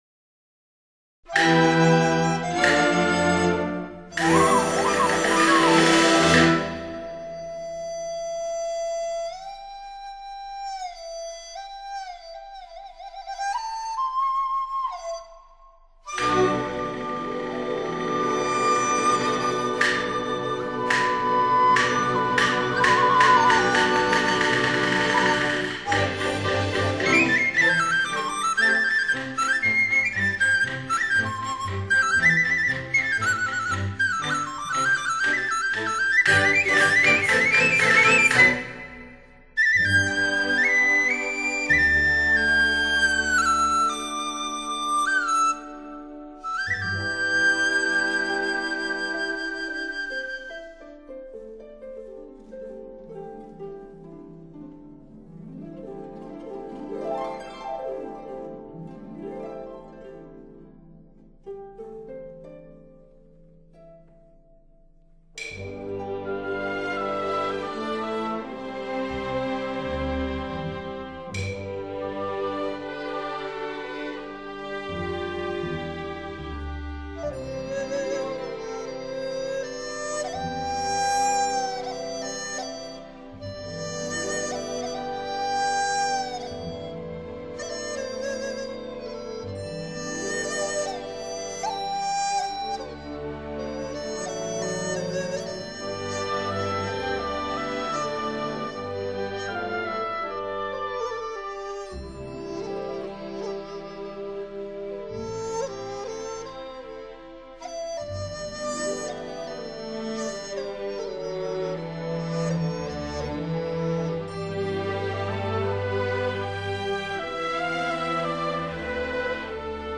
笛艺立足南派，兼取北派之精华，技艺交融、声情并茂。
排笛独奏
作者运用低音笛与梆笛交替演奏
富有浓郁的戏曲风味